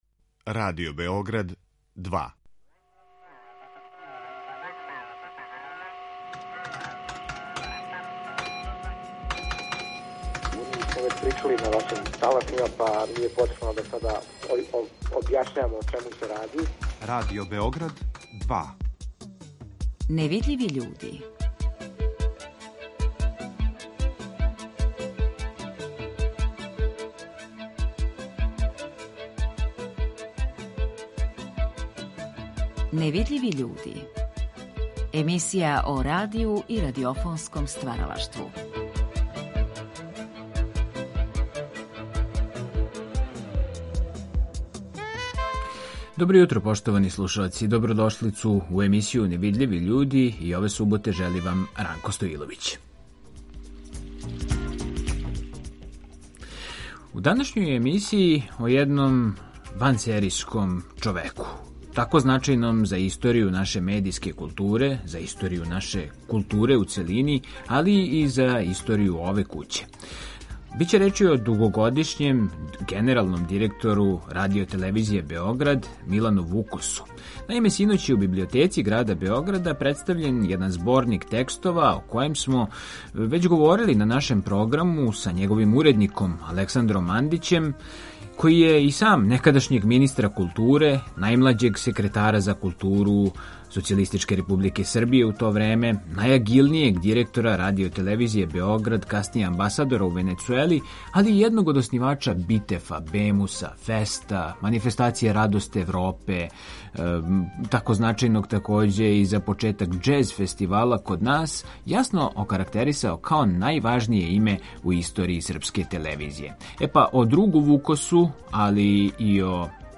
а емитујемо и архивске снимке гласа Милана Вукоса, сачуване у нашој Фонотеци.